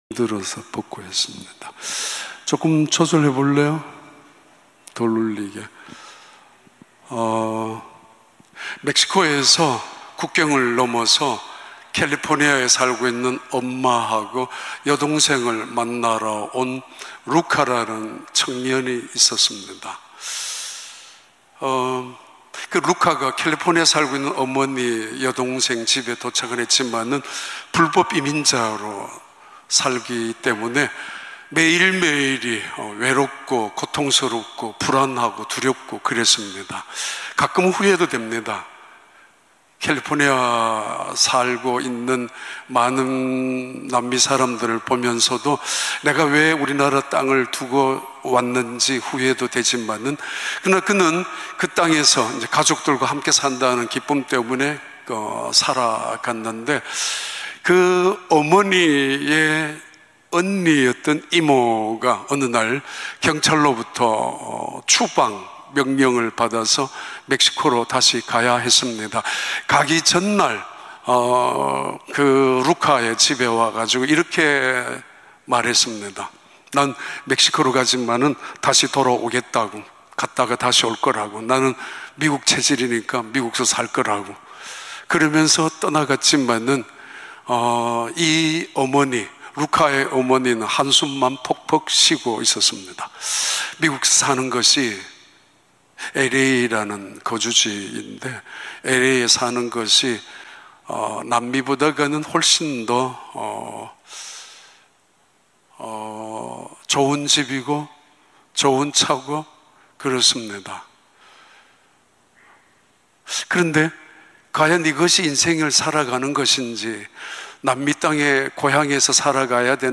2022년 5월 22일 주일 3부 예배